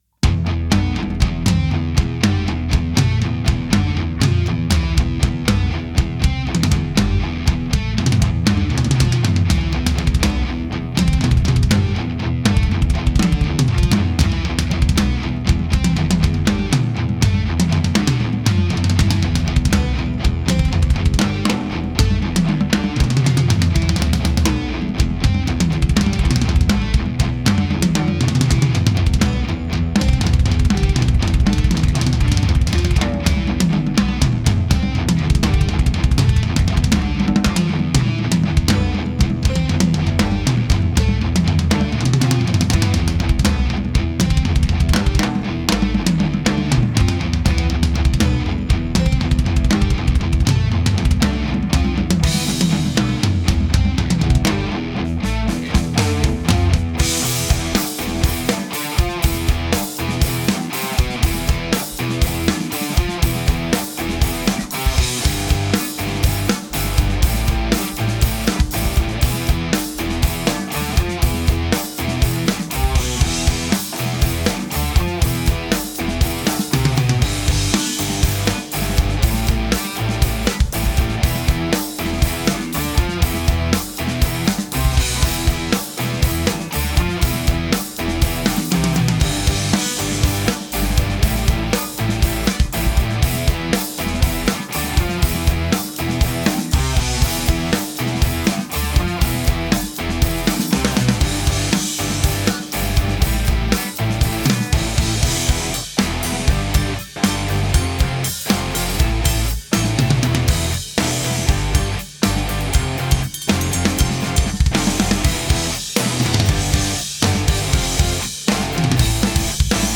Playalong und Performance